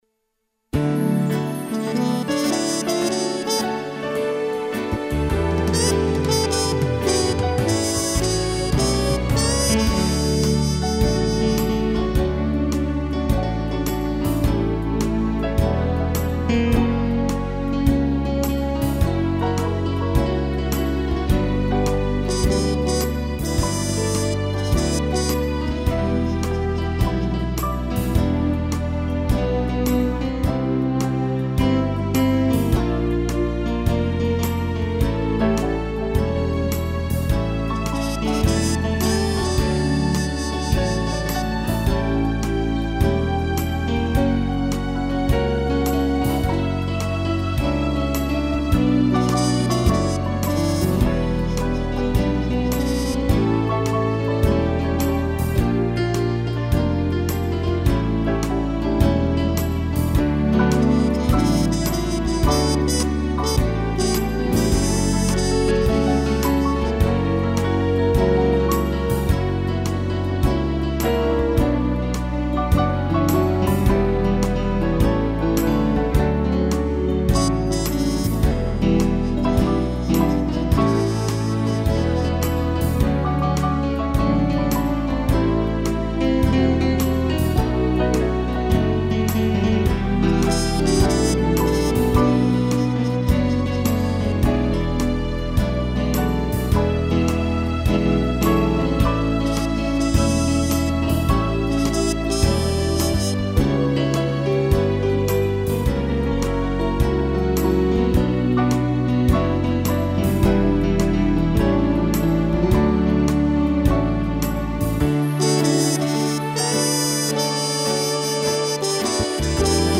piano, sax e strings
(instrumental)